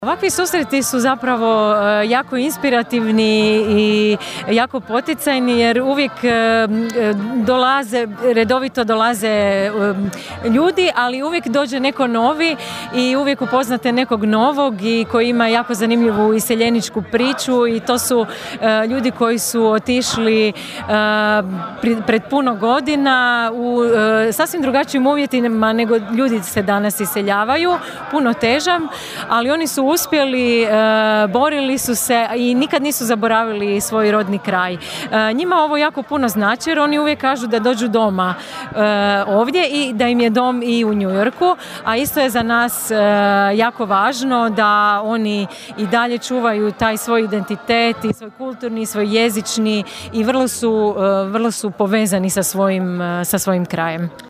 Stotinjak iseljenika koji trenutačno borave u domovini, uglavnom iz SAD-a, ali i Kanade te europskih zemalja Francuske i Njemačke, okupilo se sinoć u Konobi 'Bukaleta' na Dubrovi, na Godišnjem susretu iseljenika Labinštine.